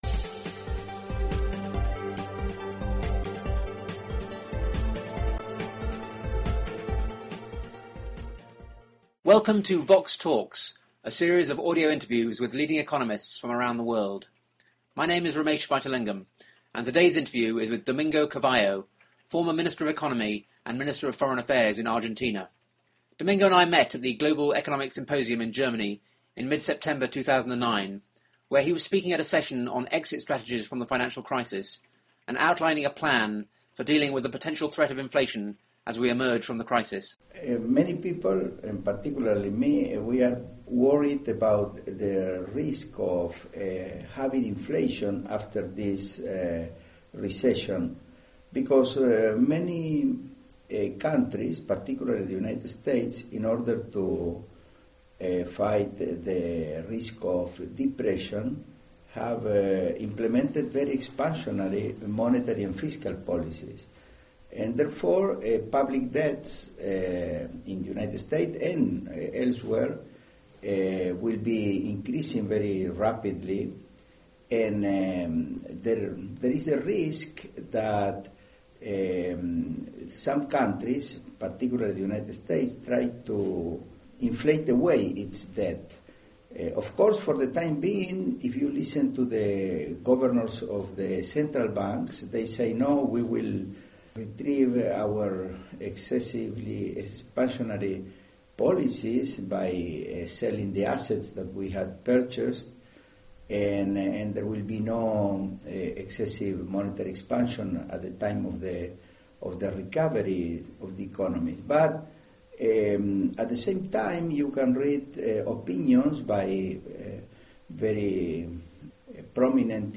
The interview was recorded at the Global Economic Symposium in Schleswig-Holstein in September 2009.